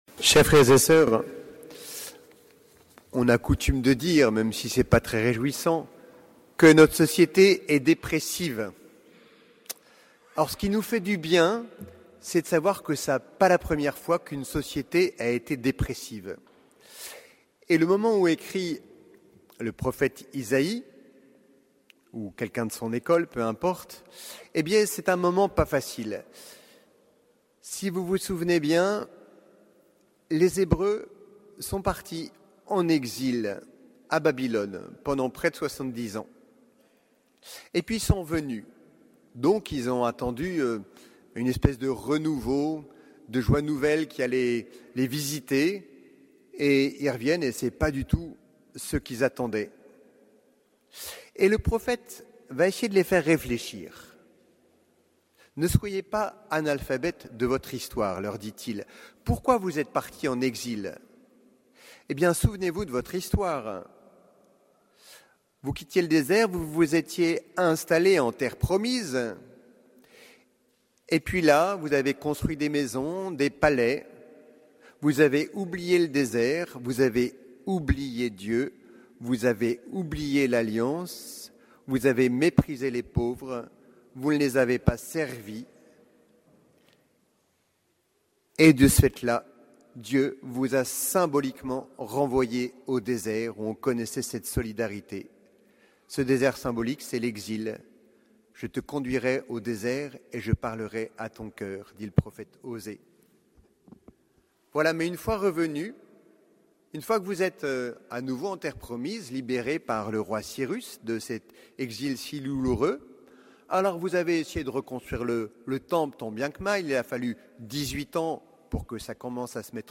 Homélie du troisième dimanche de Pâques